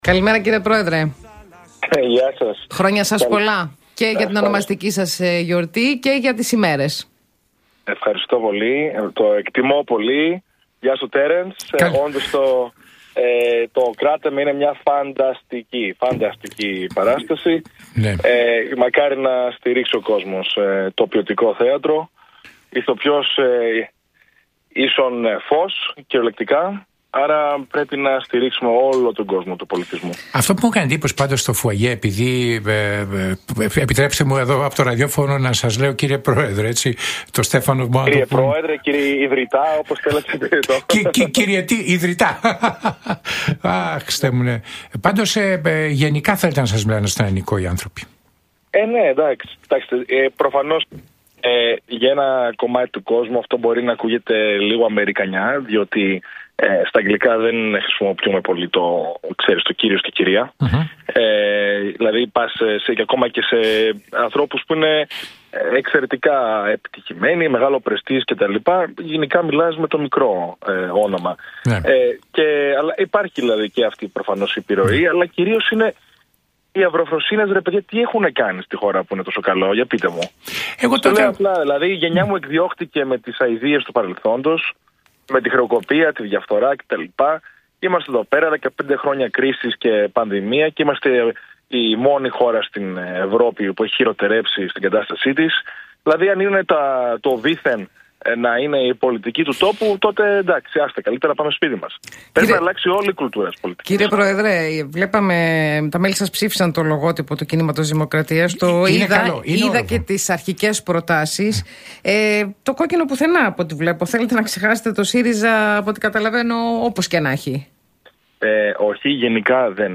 Συνέντευξη παραχώρησε το πρωί της Παρασκευής